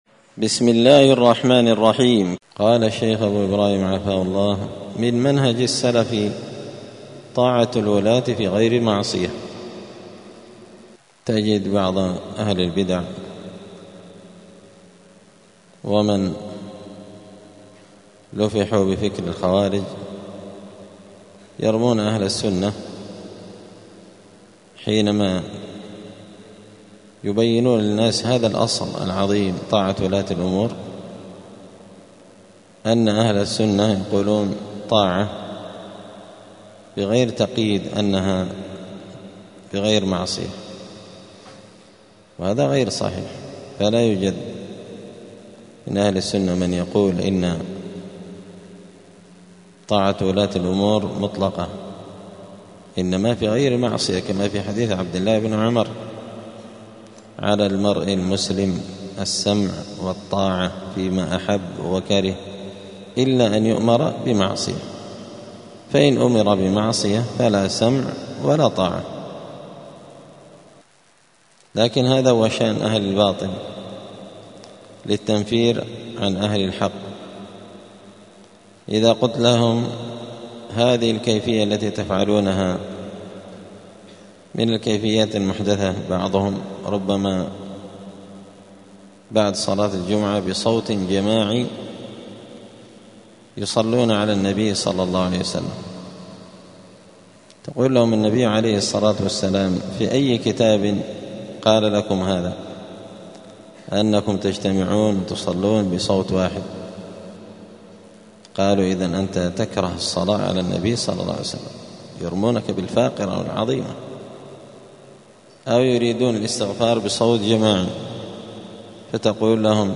دار الحديث السلفية بمسجد الفرقان بقشن المهرة اليمن
*الدرس الثاني والسبعون (72) {من منهج السلف طاعة الولاة في غير معصية}*